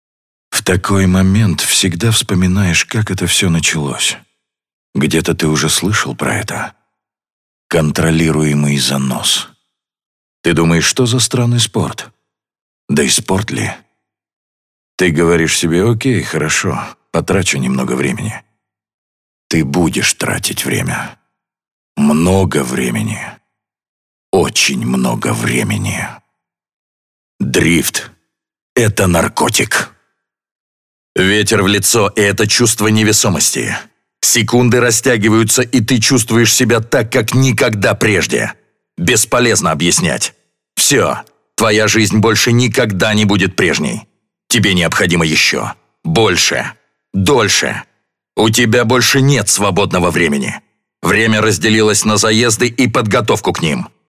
Глубокий, выразительный, харизматичный тембр, идеально подходящий как для дубляжа голливудских звёзд так и для рекламных роликов